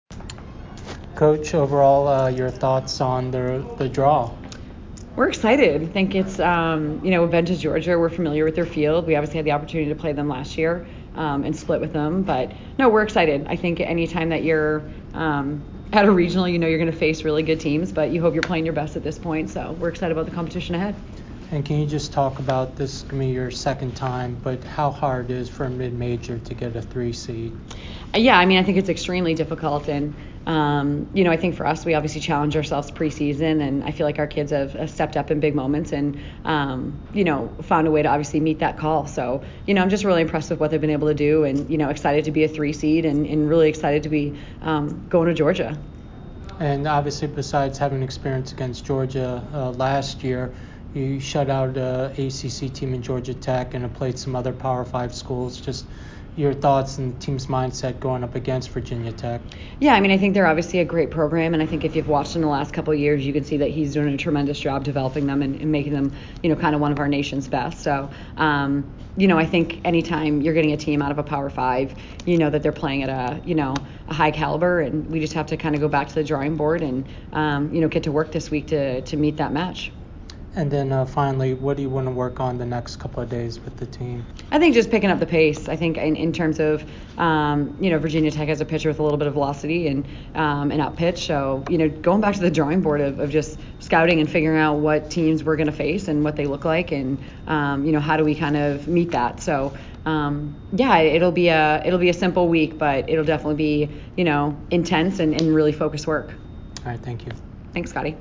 NCAA Softball Selection Show Interview